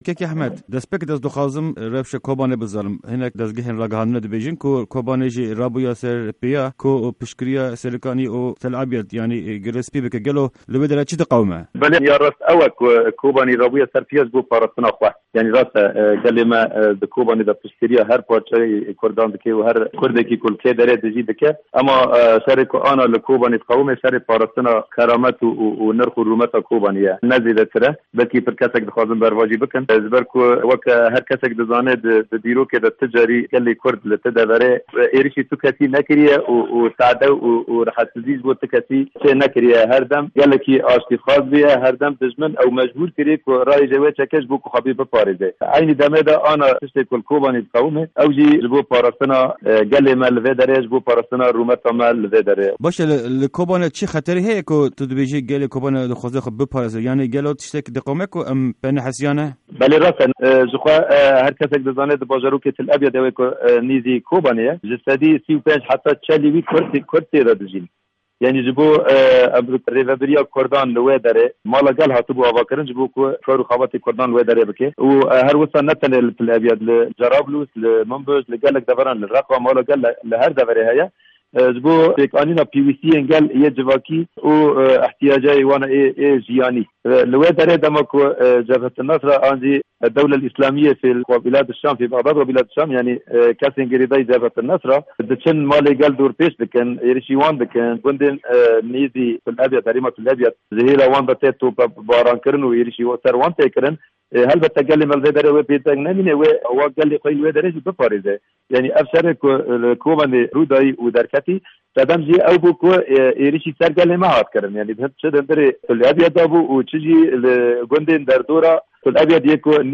Di hevpeyvîna Dengê Amerîka de Ahmed Şêxo, Hevserokê Meclîsa Gel ya Kobanê, ser şerê berdewam agahîyên herî nû dide.